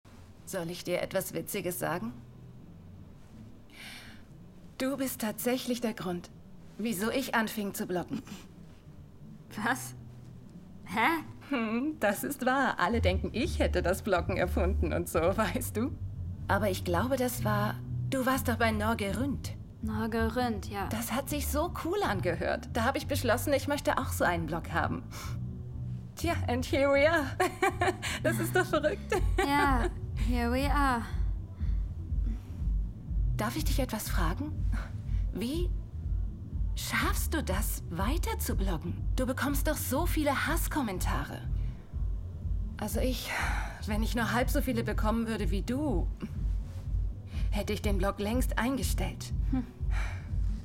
hell, fein, zart, dunkel, sonor, souverän
Mittel minus (25-45)
Lip-Sync (Synchron)